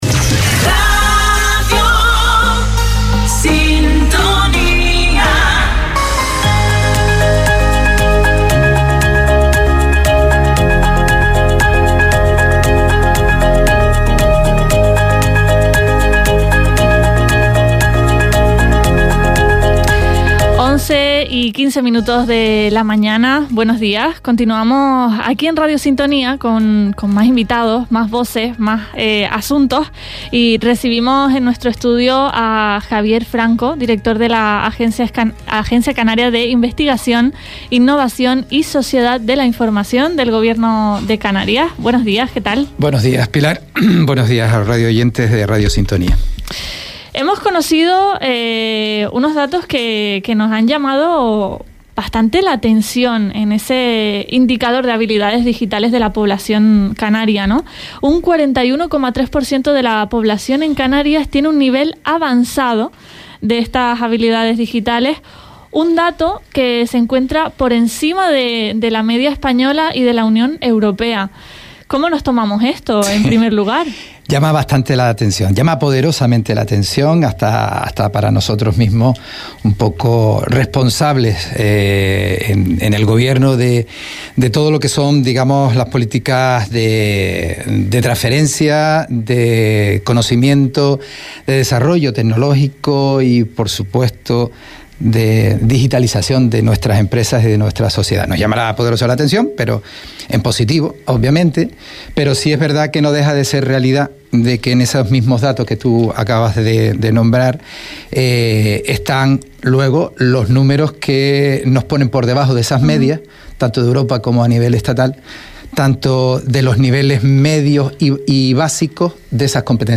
Recibimos en los estudios de Radio Sintonía a Javier Franco Hormiga, director de la ACIISI